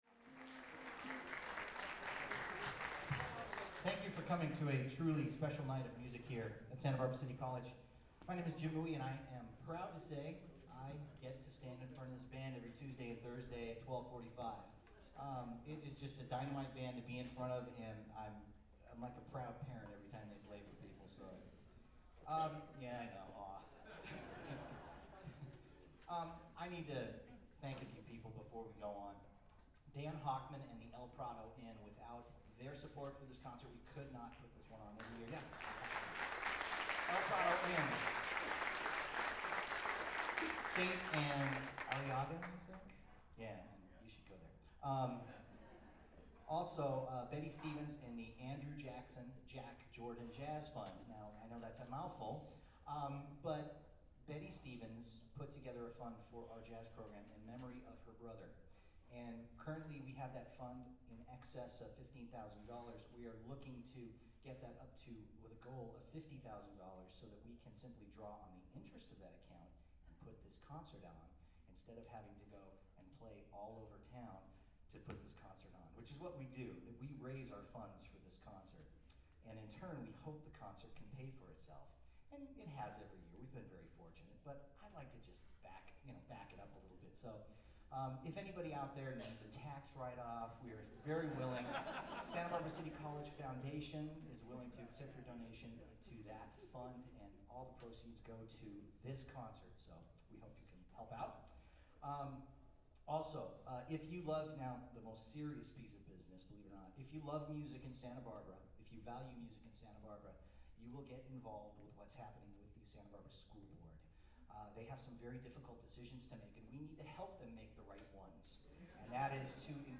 SBCC Lunchbreak Big Band in concert- April 11, 2008
6.Announcements
6.Announcements.mp3